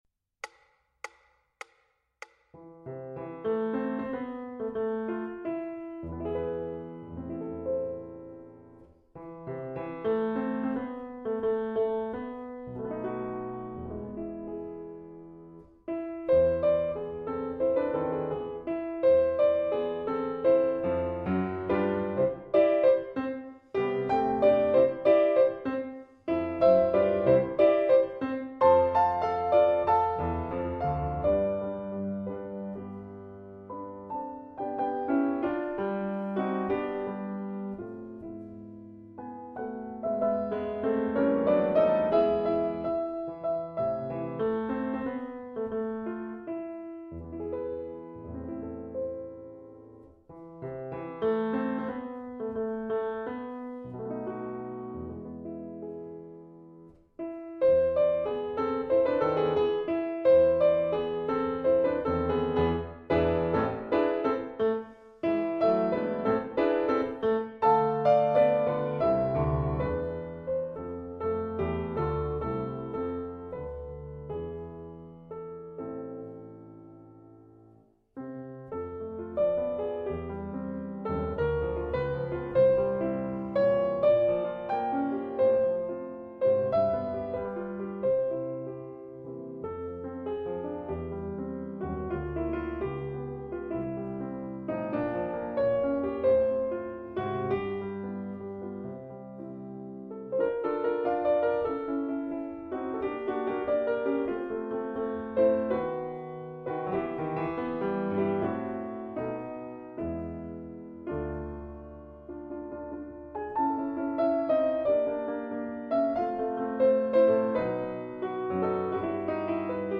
Romance no 3 Backing Track